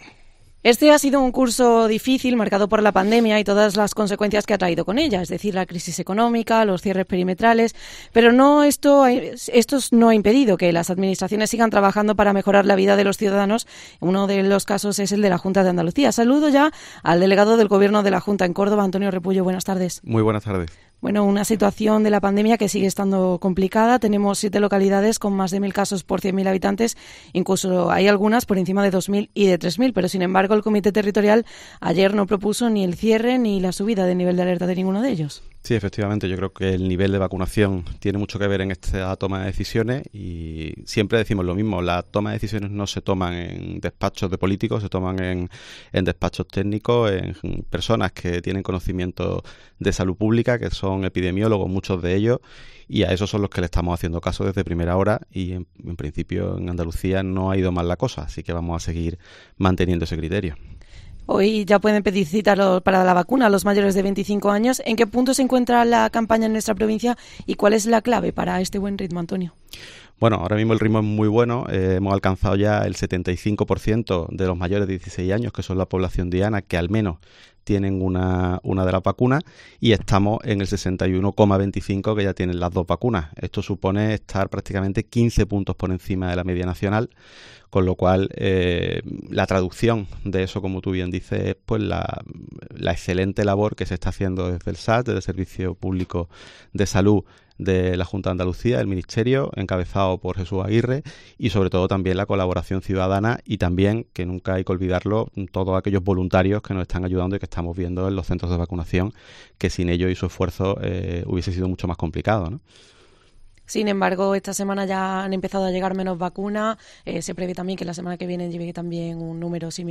Escucha la entrevista completa a Antonio Repullo, delegado del Gobierno de la Junta de Andalucía en Córdoba